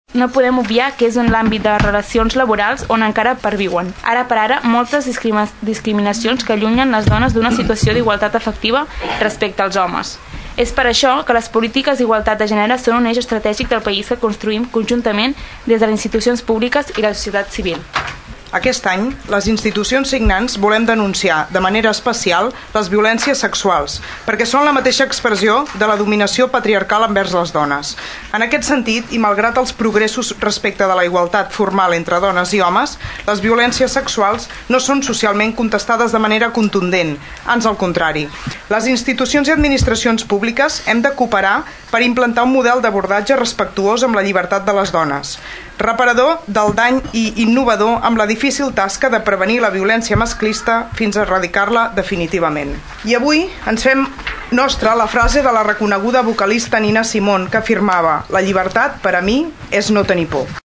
El ple de l’ajuntament de Tordera es suma a la lluita contra la violència de gènere amb la lectura d’un manifest